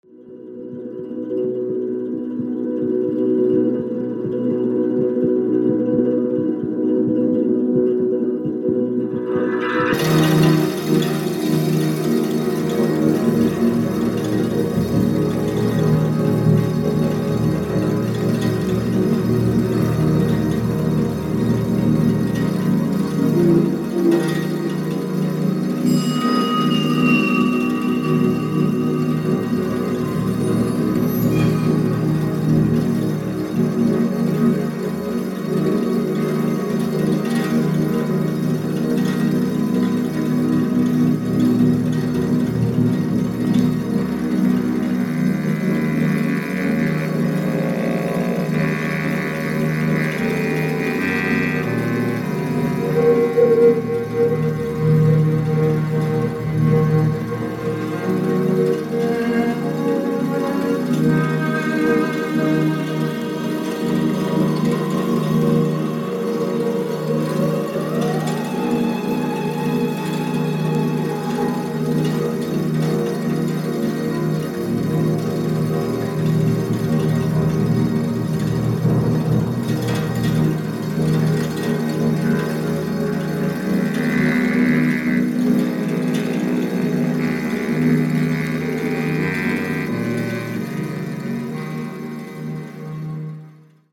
NEW AGEにちょっとFUSIONも混ざっててステキです！！！